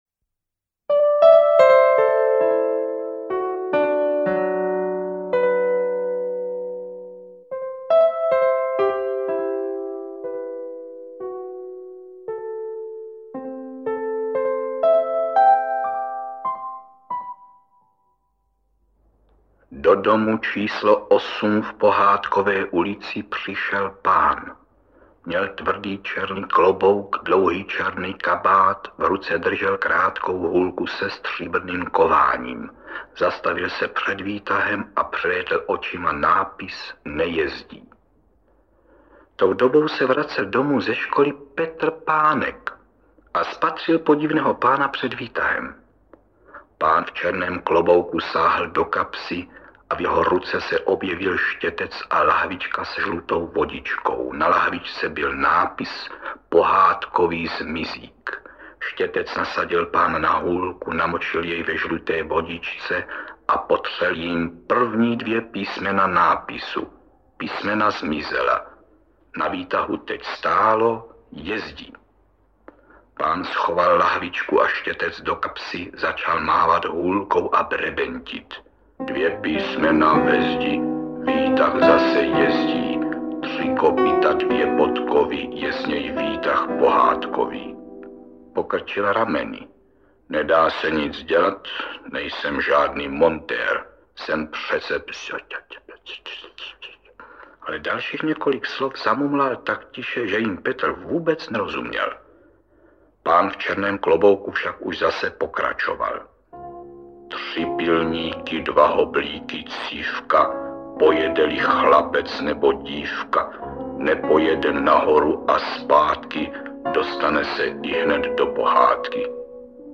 Interpret:  Karel Höger
AudioKniha ke stažení, 4 x mp3, délka 1 hod. 46 min., velikost 241,4 MB, česky